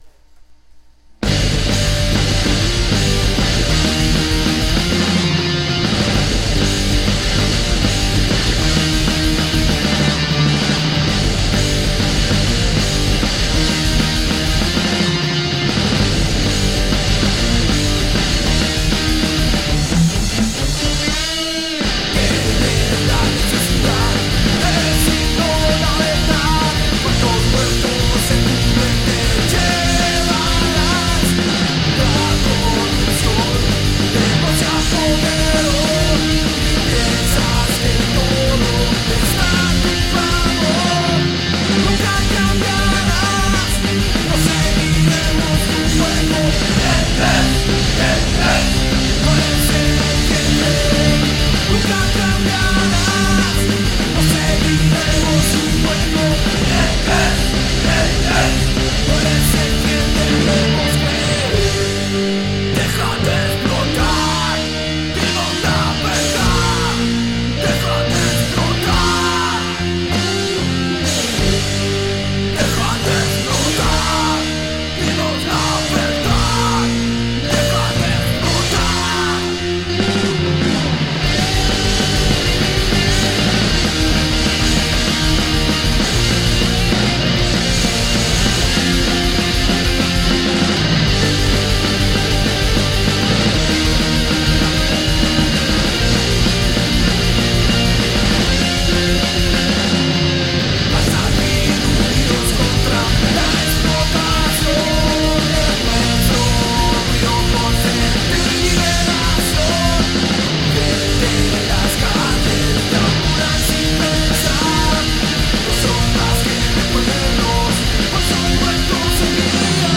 Skate-Punk Hardcore